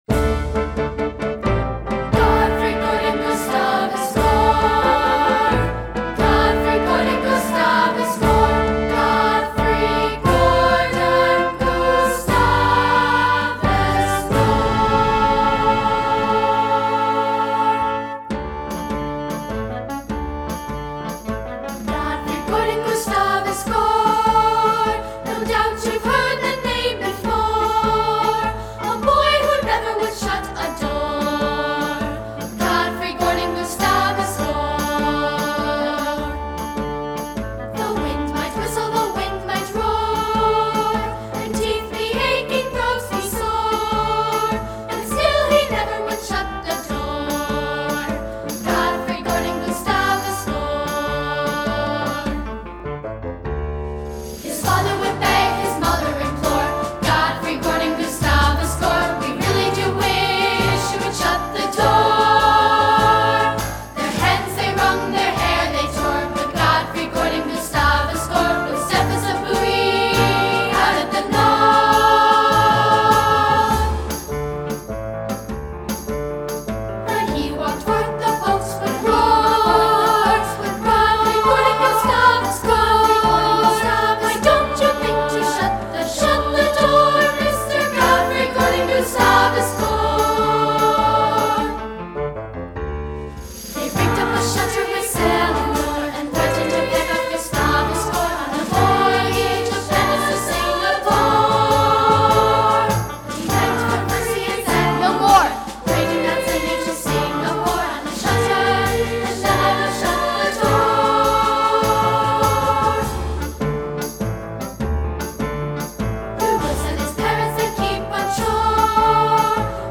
secular choral
2-part, sample